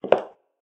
inside-step-1.ogg